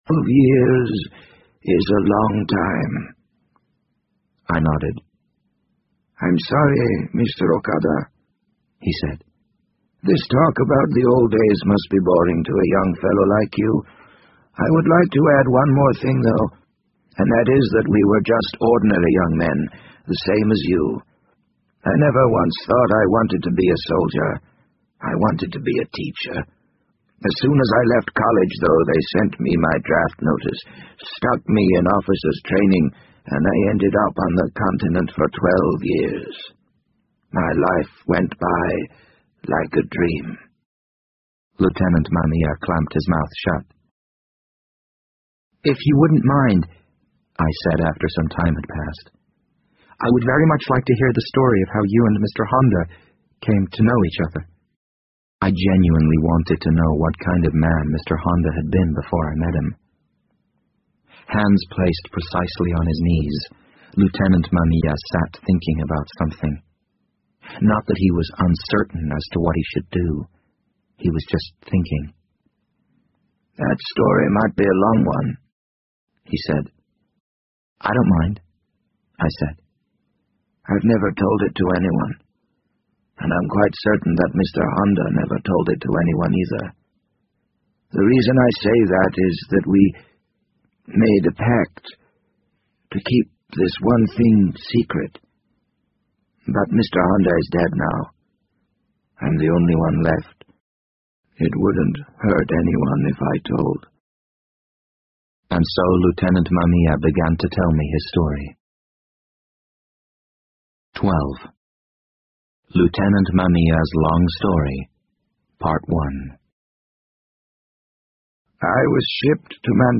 BBC英文广播剧在线听 The Wind Up Bird 004 - 3 听力文件下载—在线英语听力室